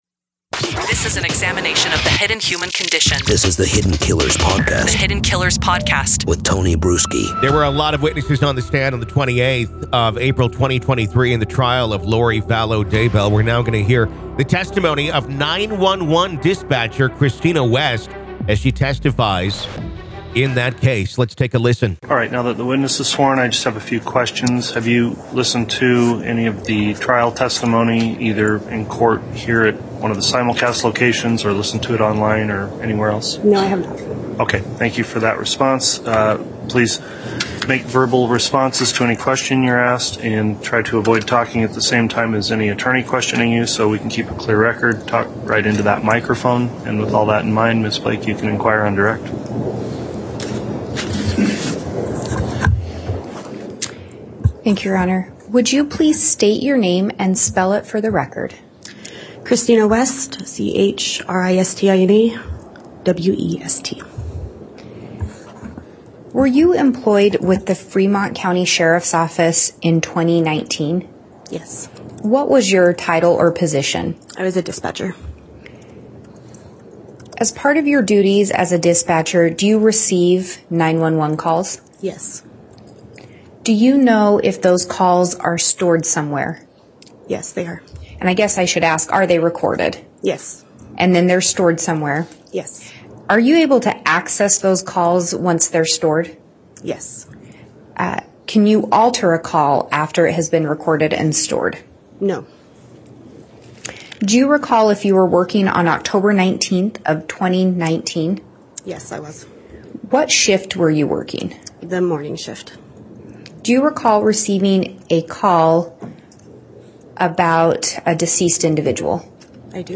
The Trial Of Lori Vallow Daybell | Full Courtroom Coverage